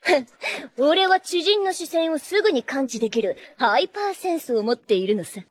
🔻Voice🔻